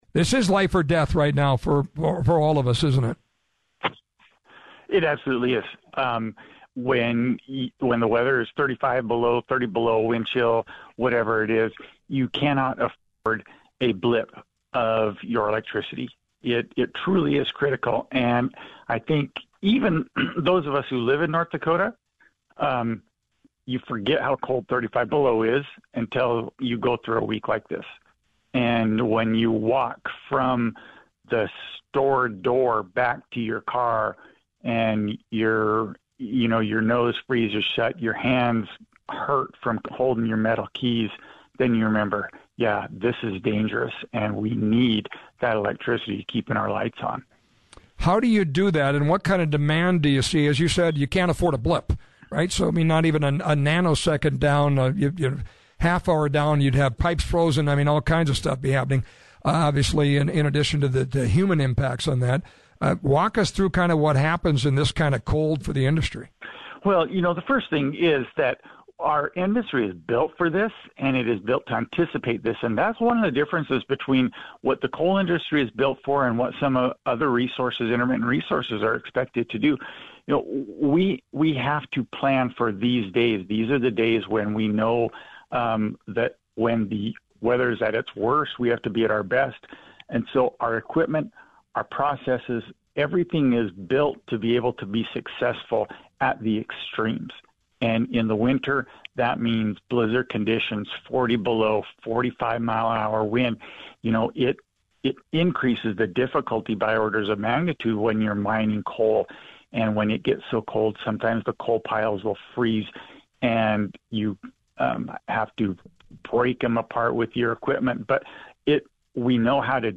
nd-coal-interview.mp3